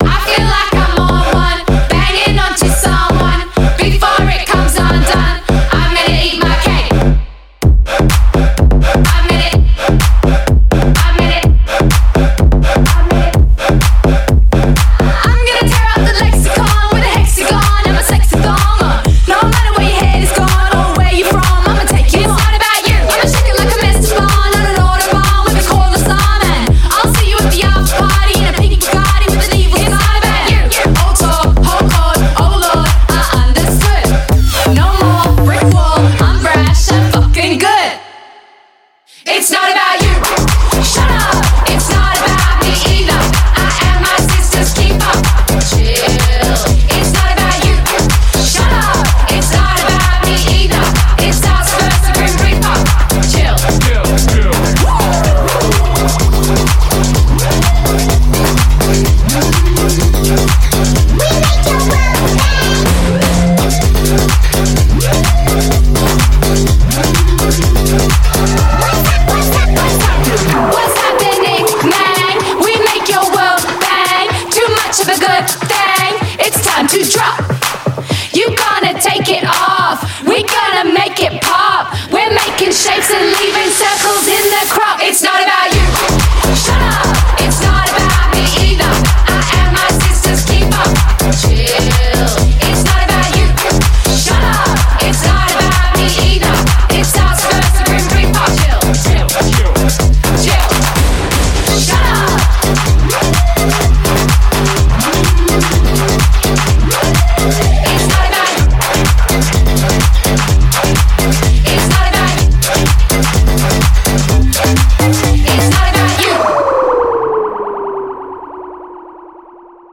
BPM126
Audio QualityMusic Cut
CommentsThe debut song from Australian dance pop group.